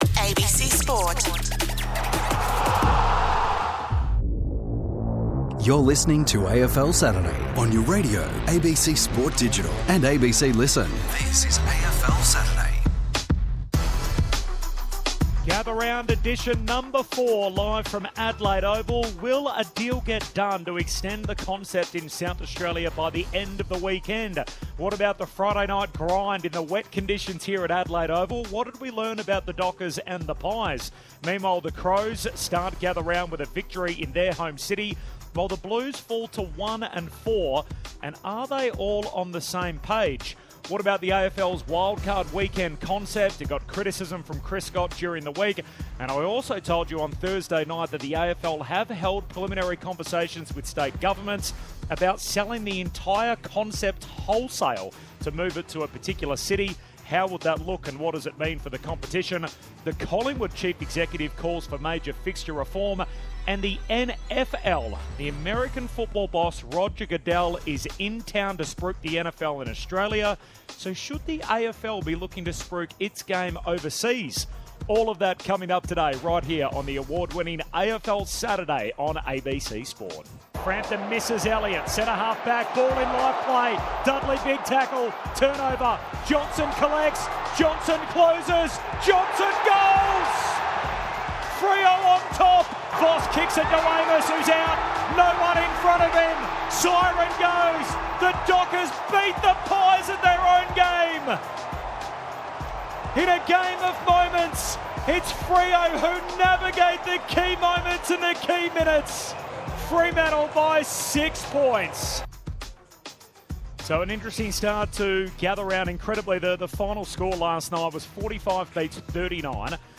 Today, the team are in Adelaide with the wash up from Fremantle's come from behind win over Collingwood in the wet, and discuss the future of the Gather Round concept, the AFL Fixture and The Wildcard Weekend.